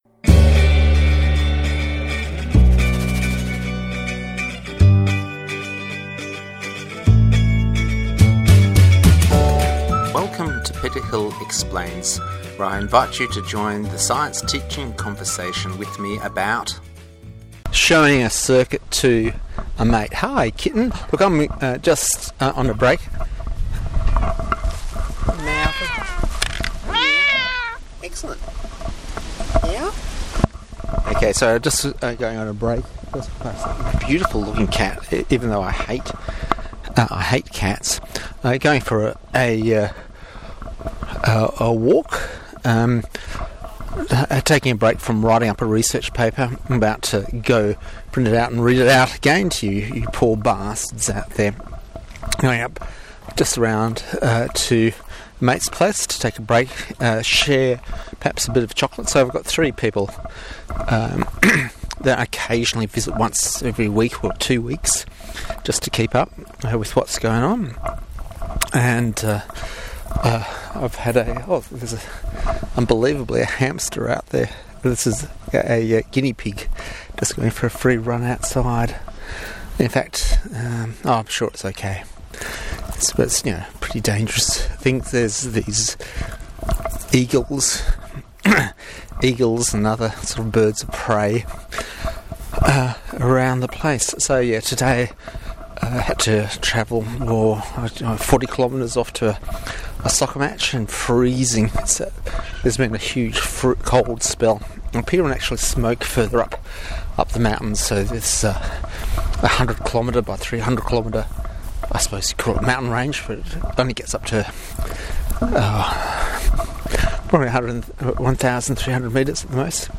Chat with Lilly Quick podcast interview with a cat and some helpers MP4 recording MP3 recording